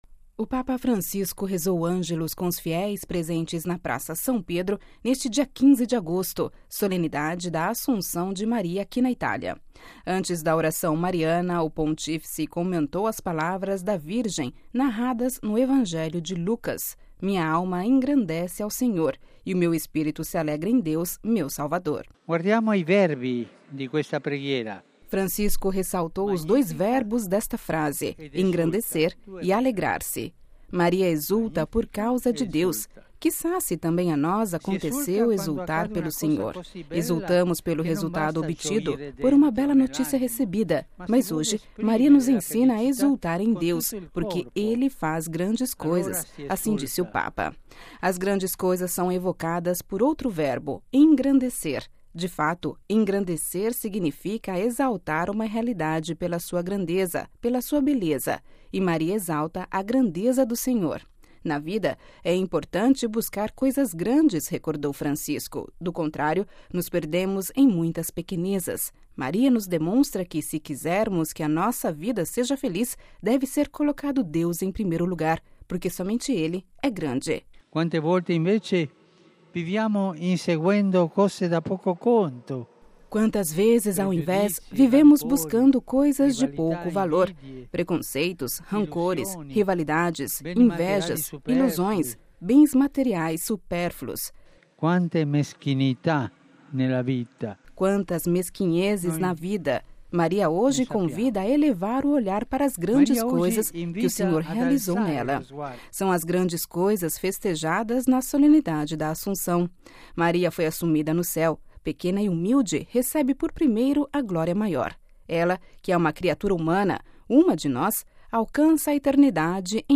O Papa Francisco rezou o Angelus com os fiéis presentes da Praça São Pedro neste dia 15 de agosto, solenidade da Assunção de Maria na Itália.
Ouça a reportagem completa com a voz do Papa Francisco